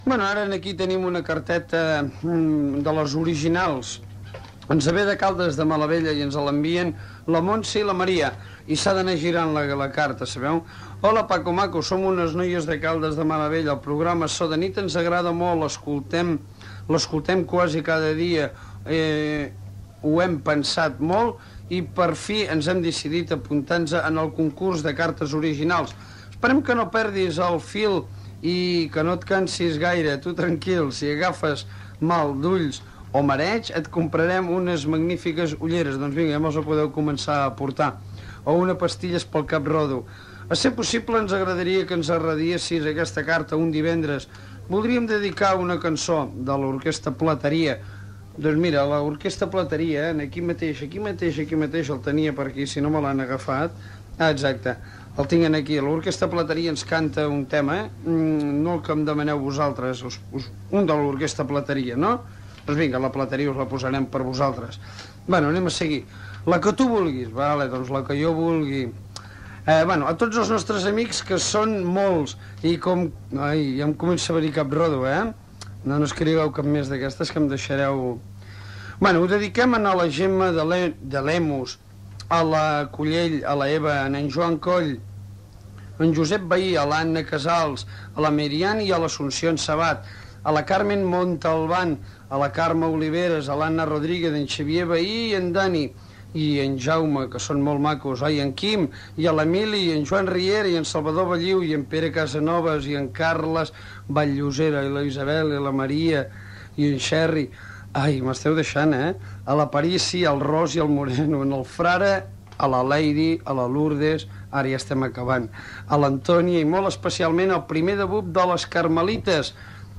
Lectura d'una carta enviada al programa dedicant un tema musical de l'Orquestra Plateria
Musical
FM